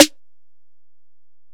Metro Snare 6.wav